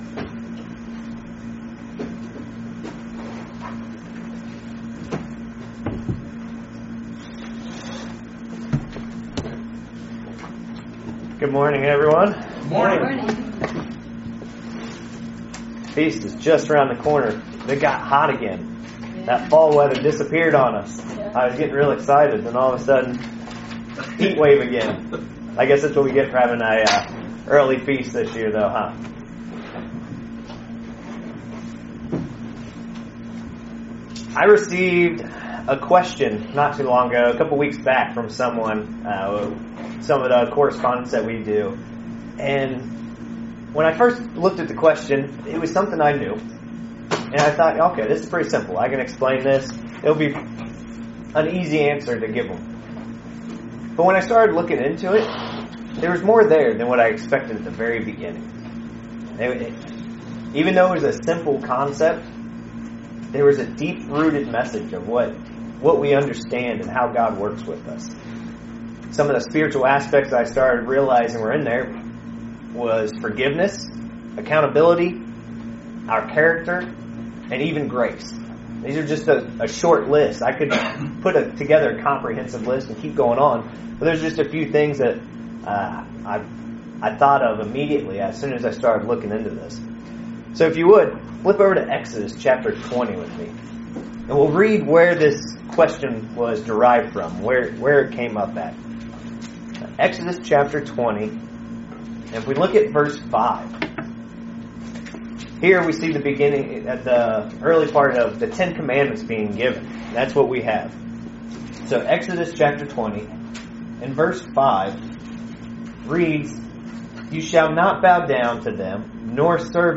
Join us for this excellent ,thought provoking sermon based on a question on the scripture in Exodus 34:7. The question was why would God Carry the sins of the father on the children to the third or fourth generation.